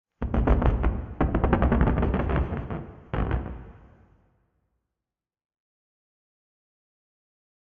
creak5.ogg
Minecraft Version Minecraft Version latest Latest Release | Latest Snapshot latest / assets / minecraft / sounds / ambient / nether / warped_forest / creak5.ogg Compare With Compare With Latest Release | Latest Snapshot